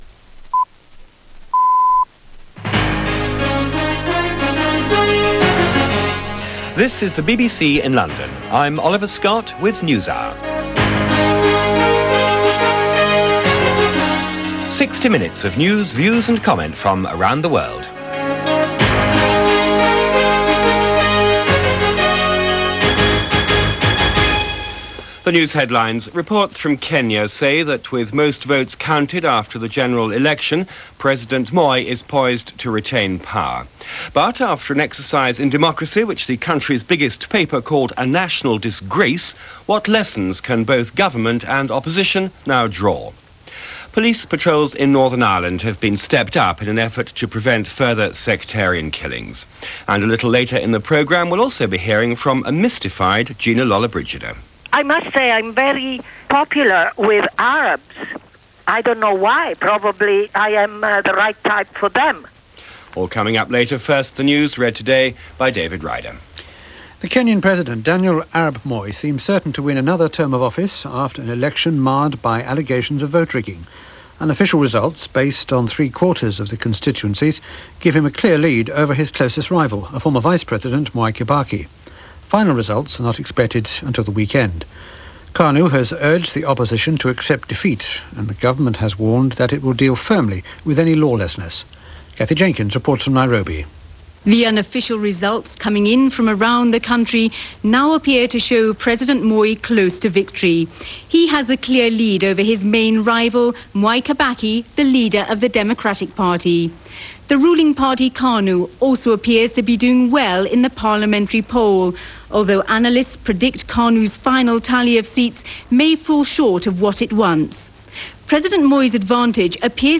BBC World Service – Newshour